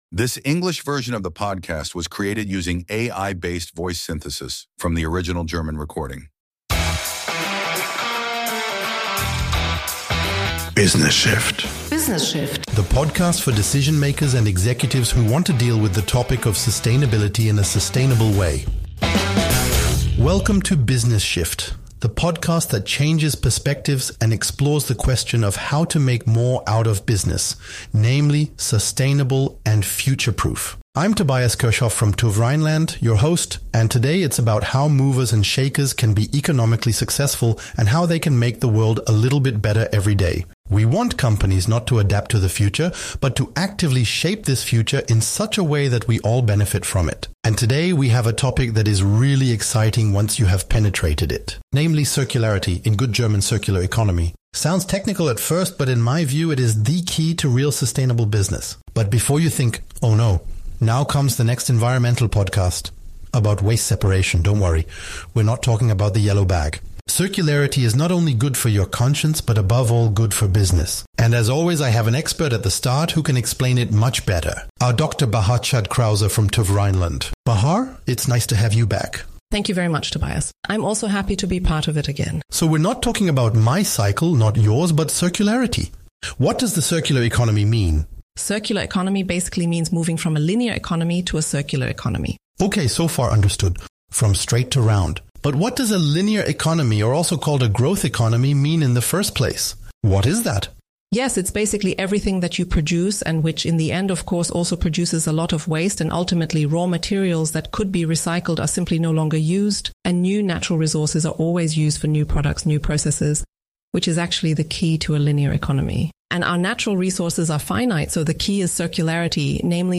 ****In compliance with AI regulations, we disclose that the English voices in this podcast episode were generated using artificial intelligence based on the original German version.**** Mehr